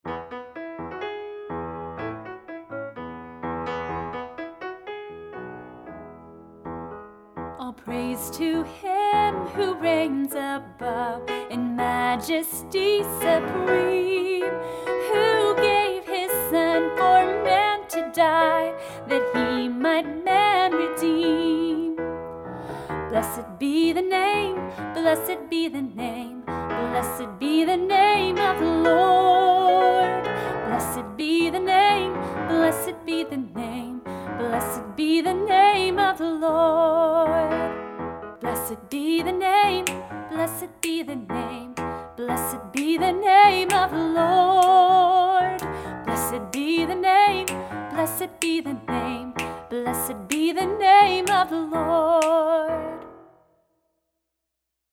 Big Q & A 11 Nursery Rhyme Song